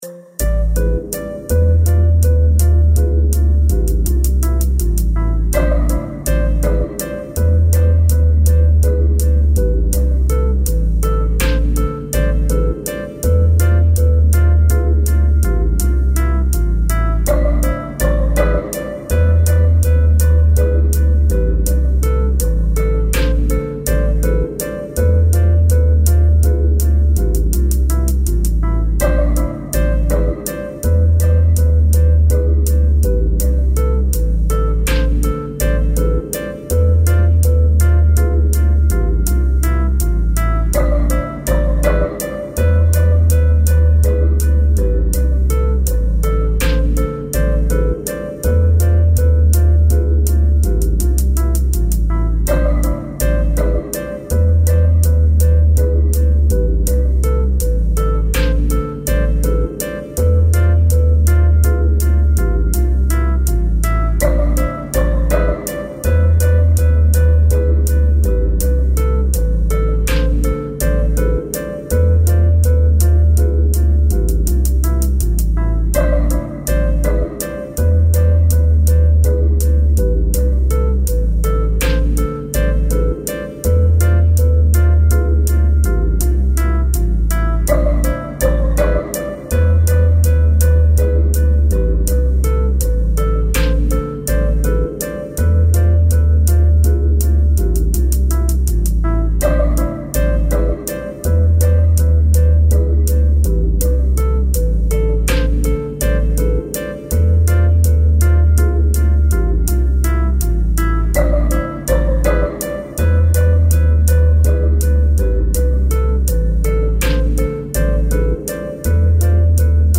File under: Avantgarde